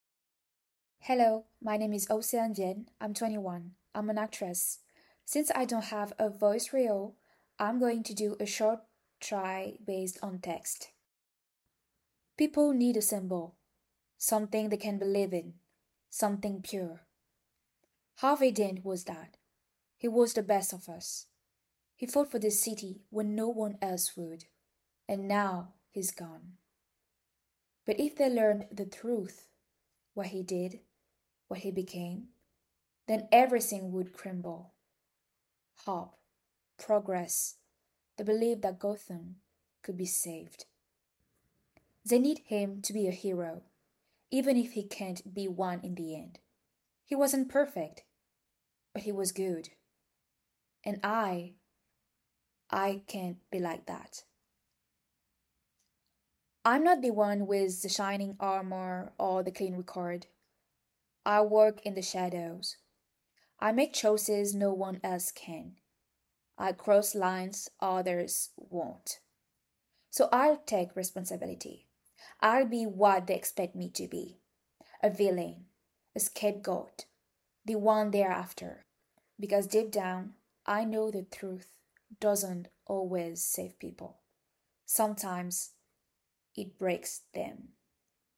Voice reel - English
10 - 30 ans - Soprano